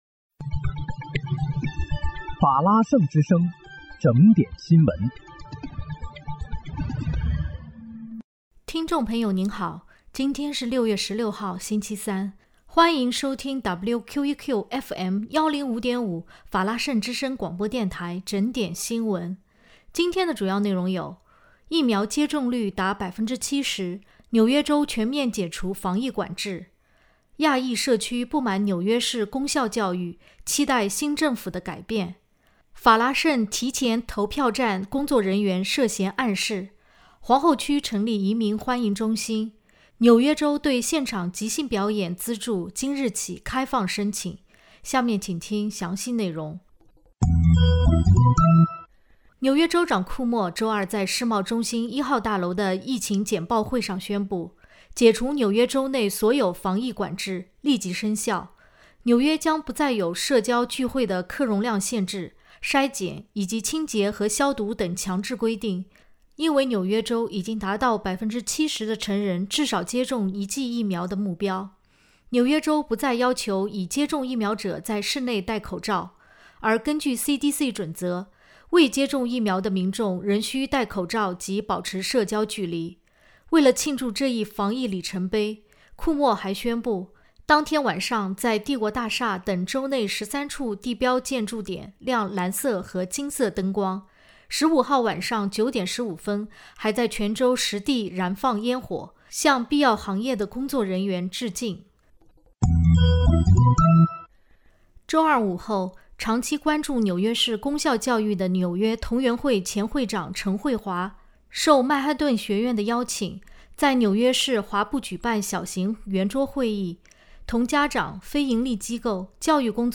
6月16日（星期三）纽约整点新闻